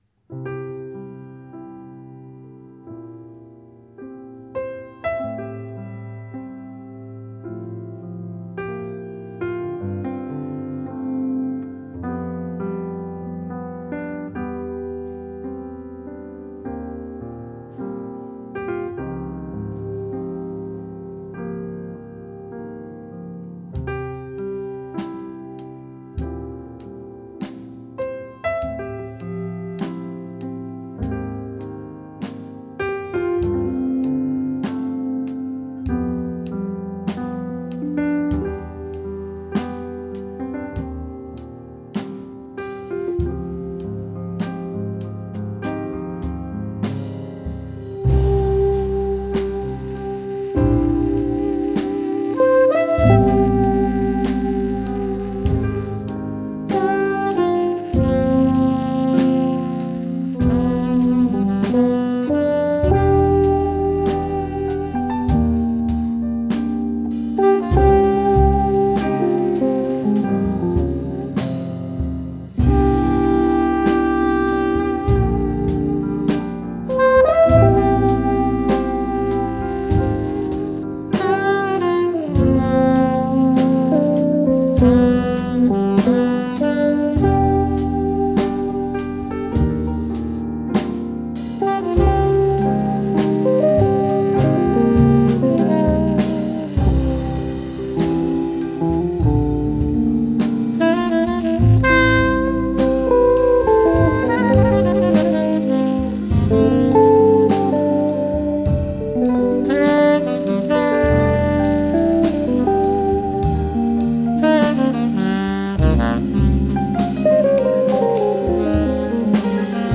saxofonista